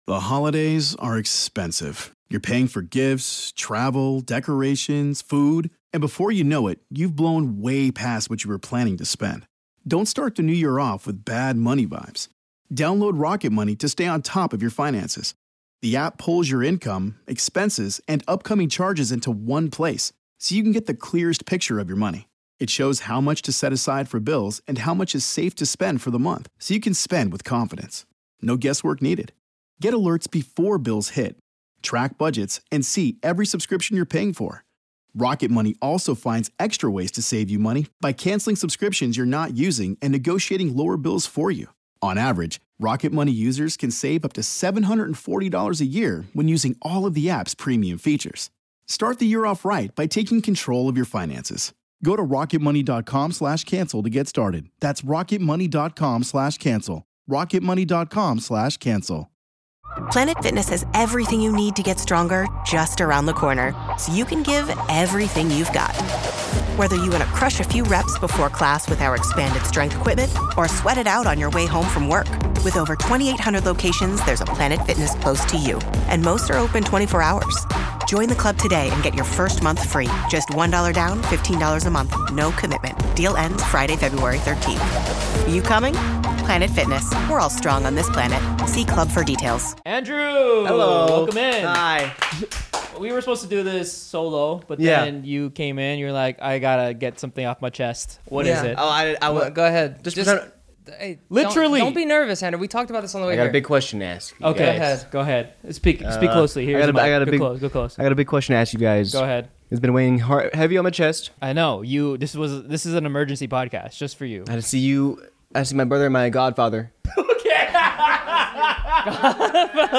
100% all access interview